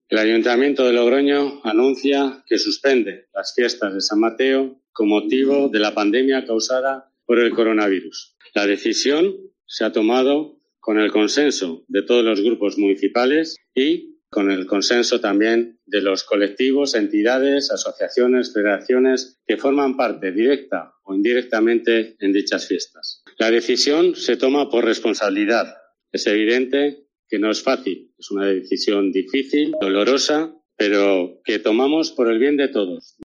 Kilian Cruz, portavoz del Ayuntamiento de Logroño